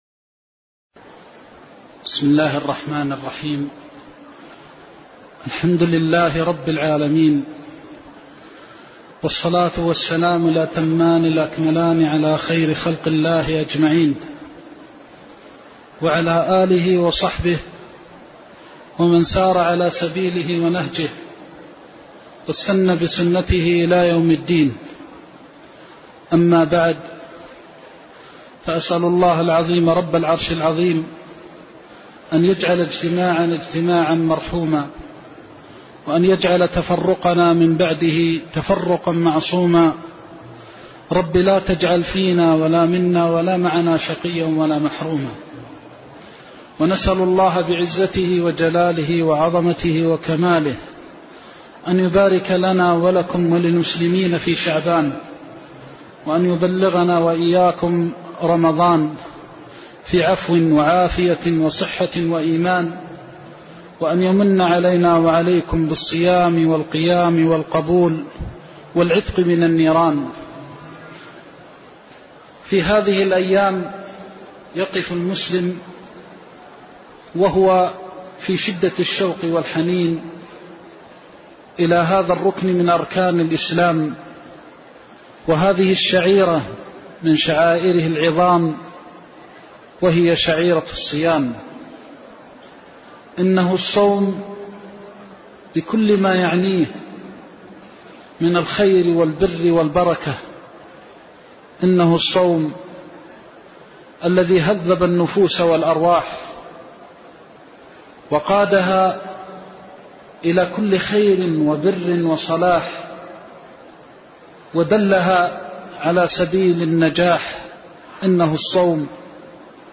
محاضرة شهر رمضان وتوجيهات للصائمين
المكان: المسجد النبوي